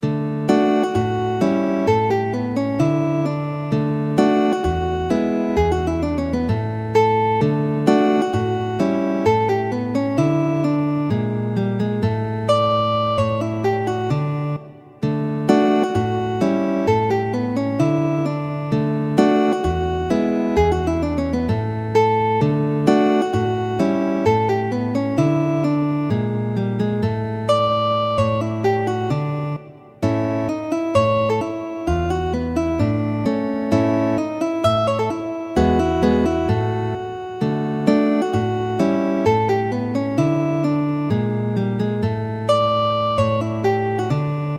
SCHERZO
SCHERZO – Allegro – in Re[tab][-♫-]